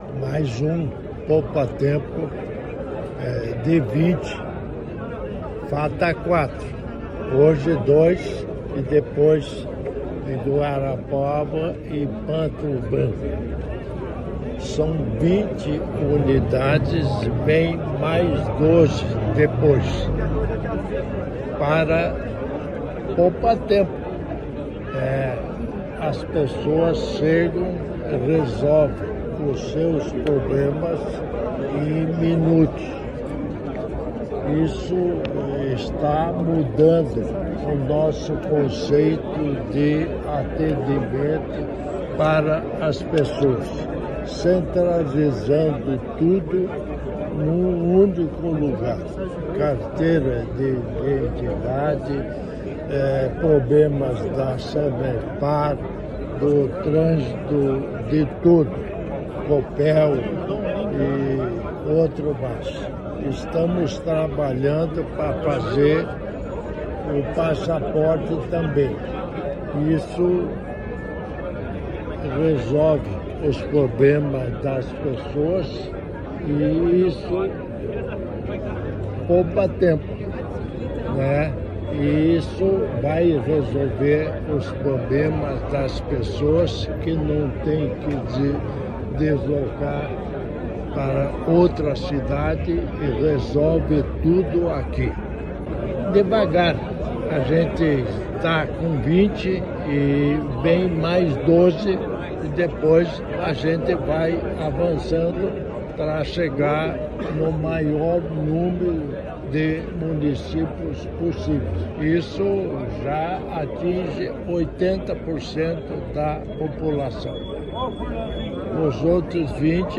Sonora do vice-governador Darci Piana sobre a inauguração da 16ª unidade do Poupatempo Paraná em Arapongas